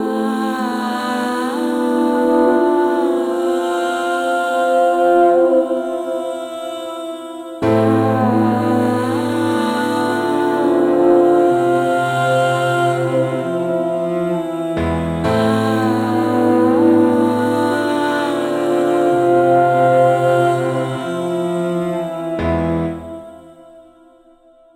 Categories: Melodies
20 High-Quality String Melodies Made Completely From Scratch.
Her-Fantasy_126BPM_Amin.wav